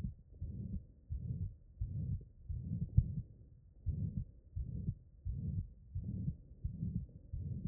sdd190-endocardite-mitrale.mp3